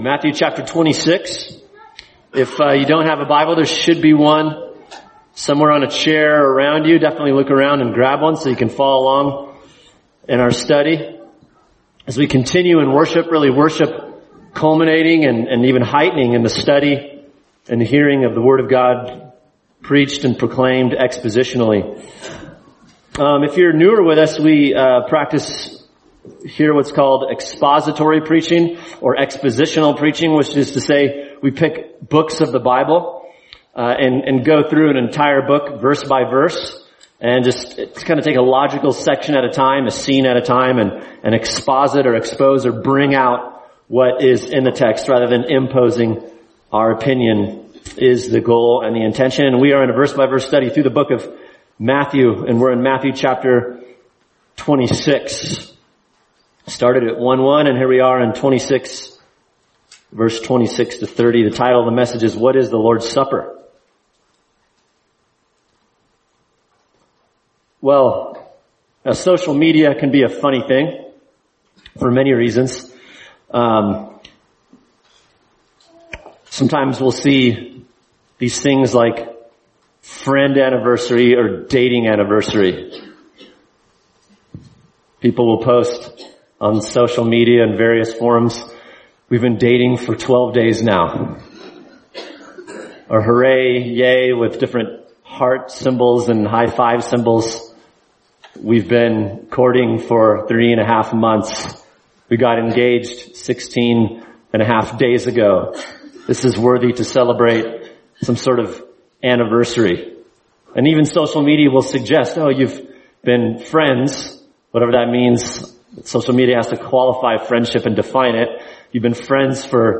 [sermon] Matthew 26:26-30 – What is the Lord’s Supper?